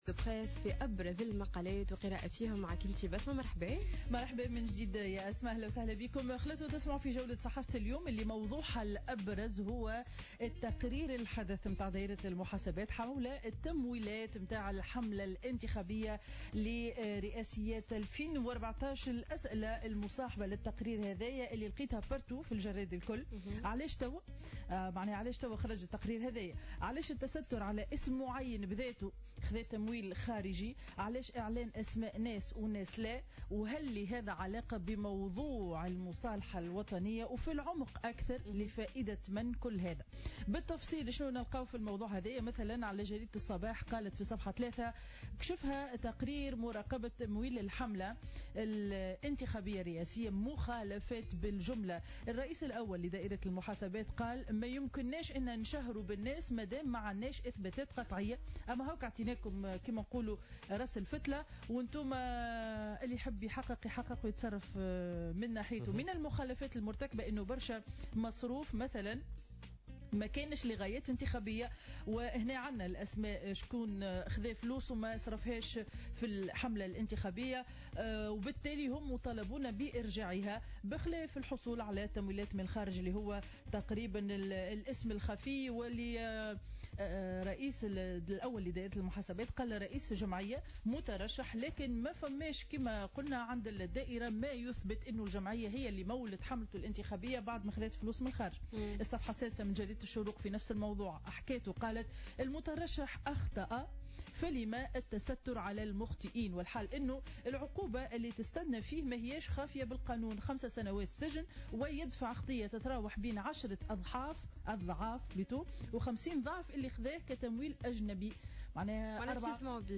Revue de presse du vendredi 21 août 2015